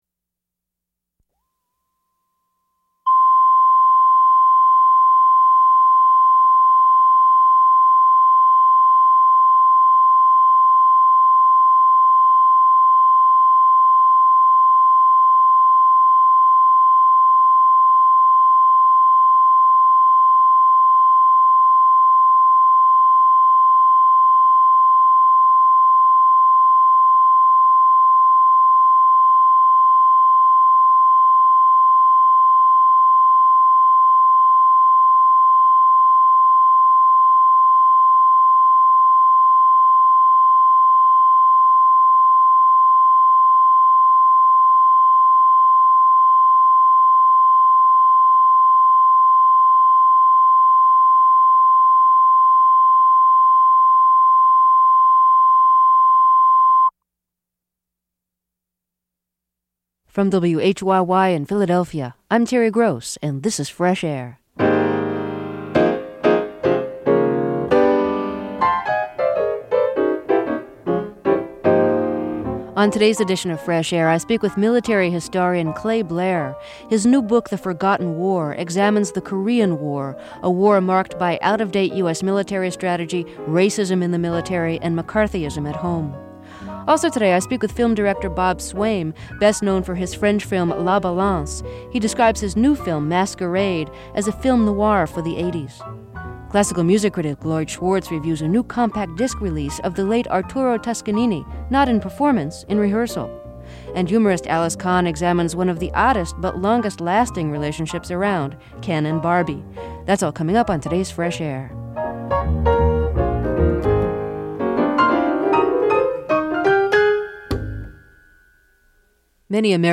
Interview Clay Blair